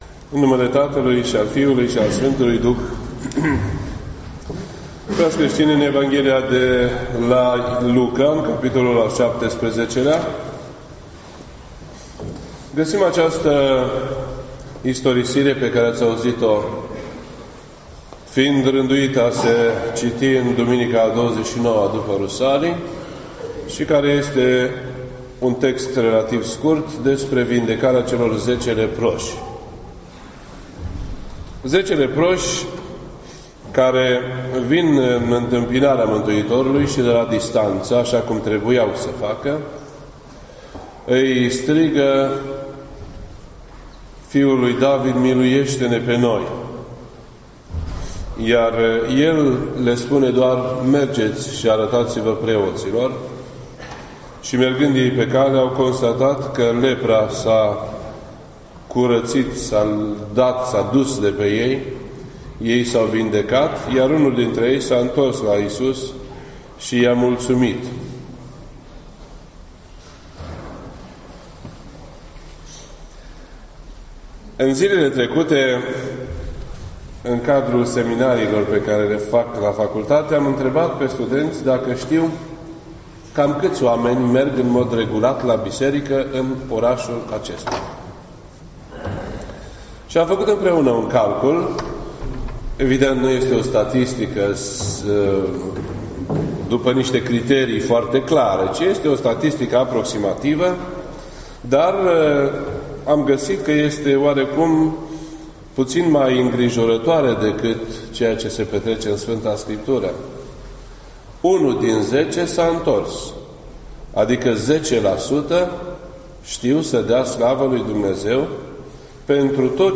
This entry was posted on Sunday, January 15th, 2017 at 8:55 PM and is filed under Predici ortodoxe in format audio.